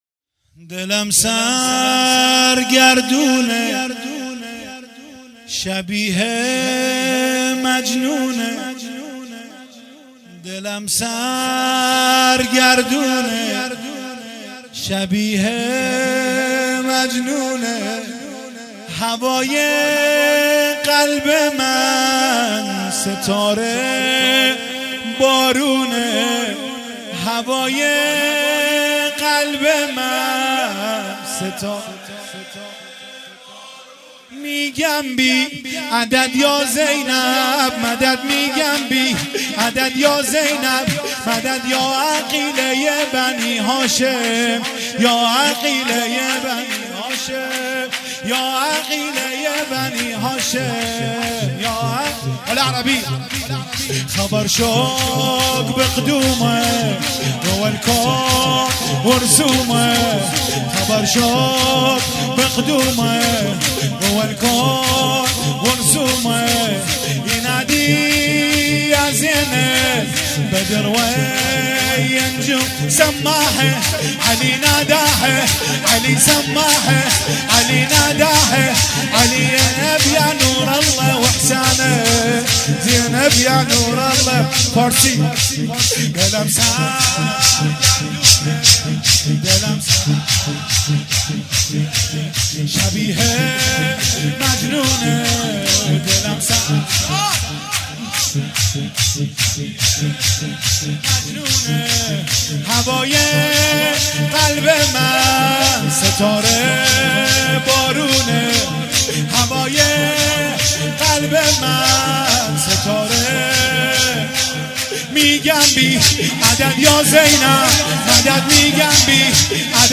مولودی- دلم سرگردونه شبیه مجنونه